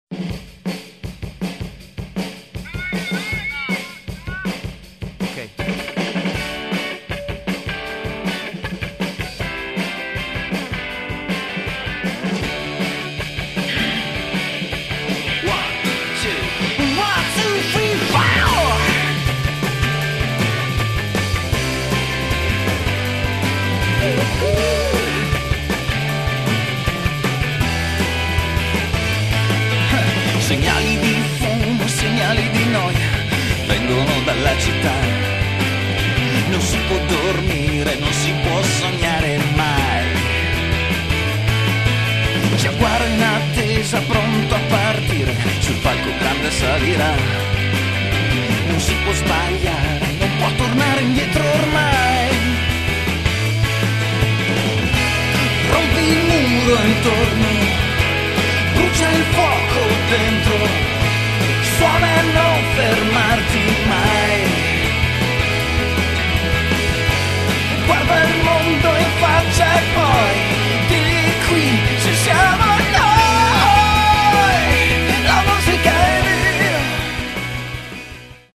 L'intro potente di batteria